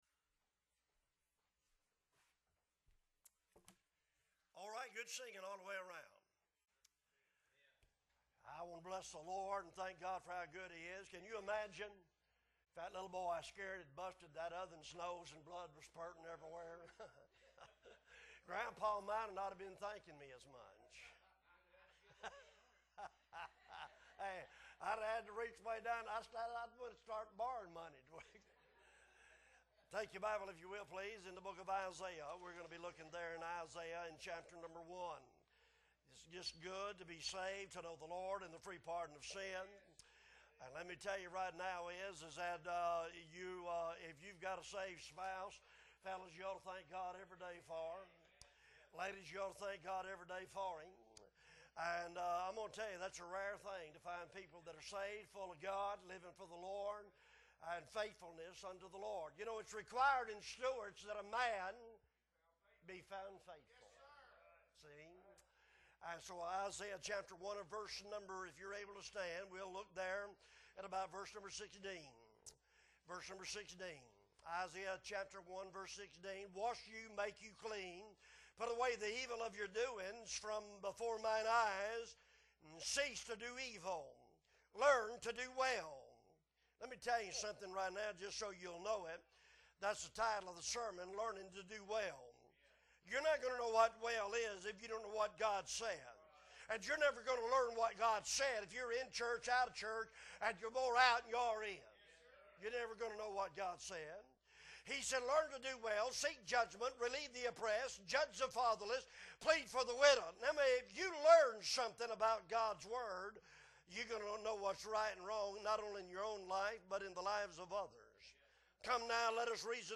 September 25, 2022 Sunday Morning Service - Appleby Baptist Church